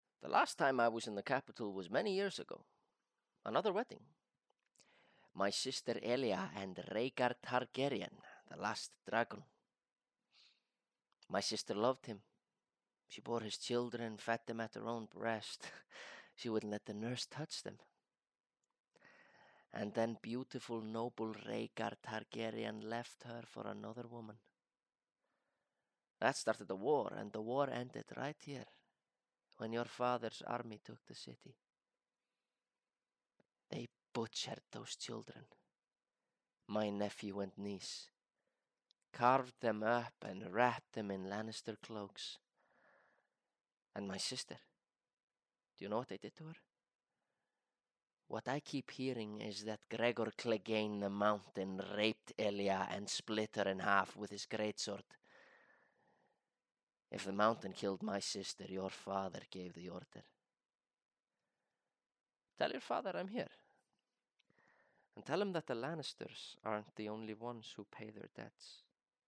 Raddprufur